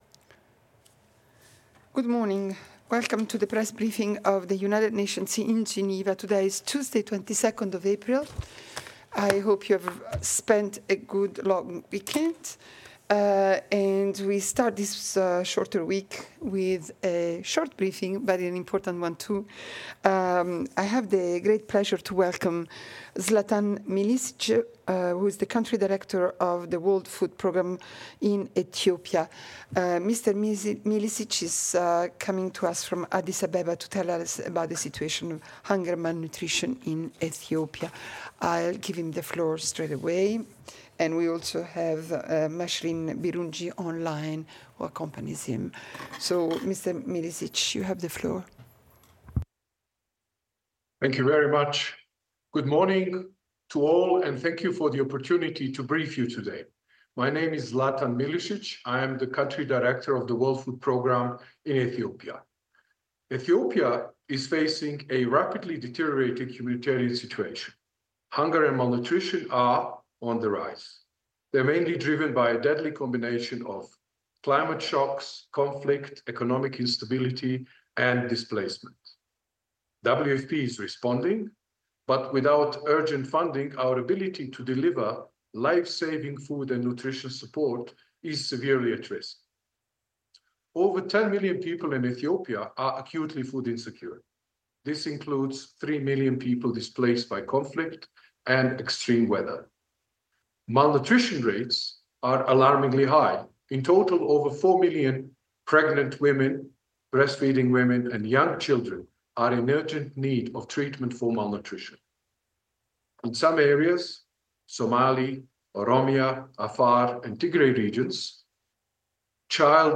Alessandra Vellucci, Director of the United Nations Information Service in Geneva, chaired a hybrid press briefing, attended by the representative of the World Food Programme (WFP).